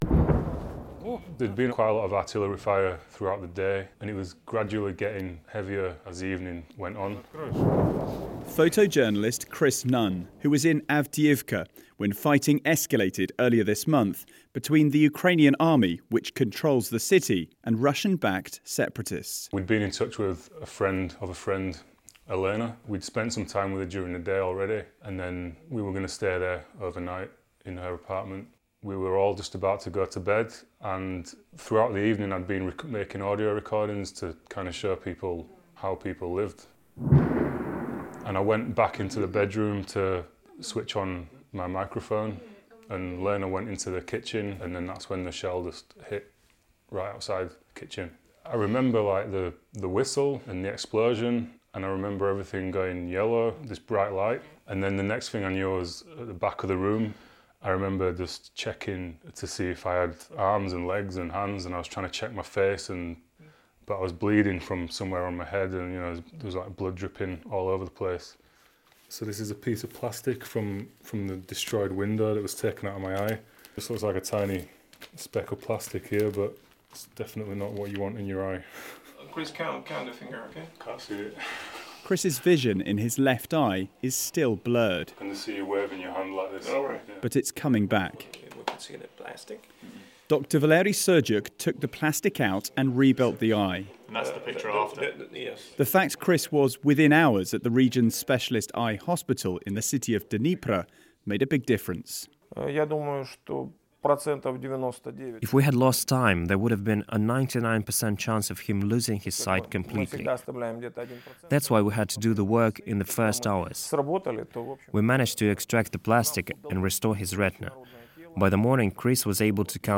British journalist's powerful story on being shelled in eastern ukraine and losing a friend who was killed in the blast.